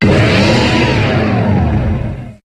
Cri de Clamiral dans Pokémon HOME.